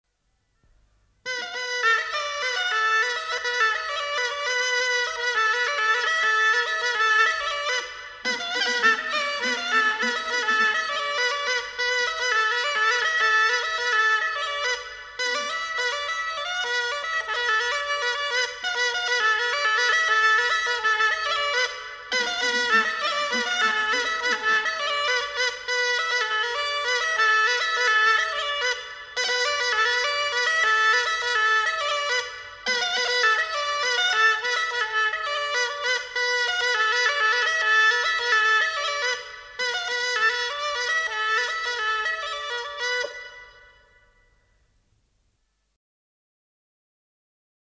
Голоса уходящего века (Курское село Илёк) Матрёнка (рожок, инструментальный наигрыш)